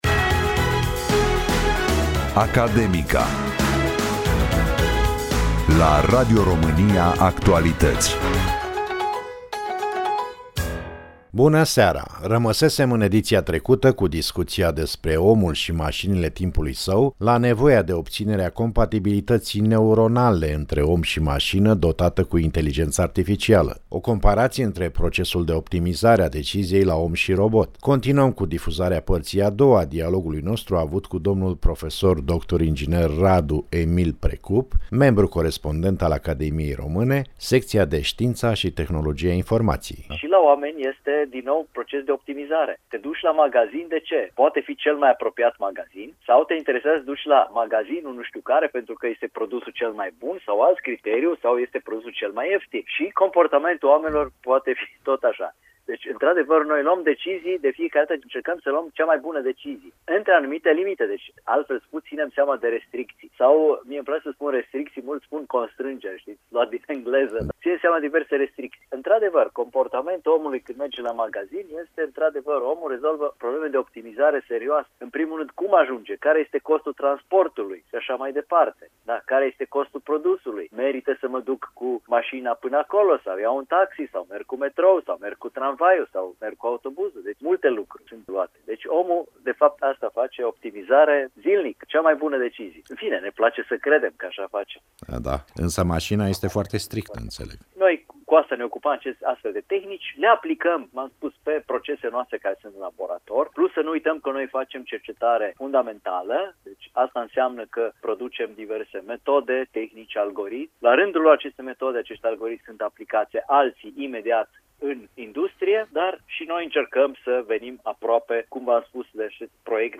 Interviu Radio România Actualități – Emisiunea „Academica“